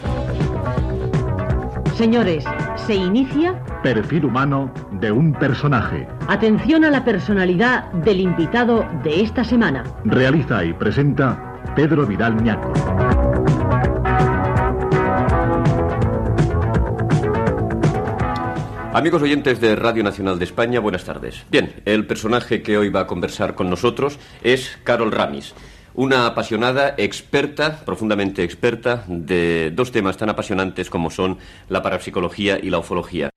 Preguntes a la invitada.
Entreteniment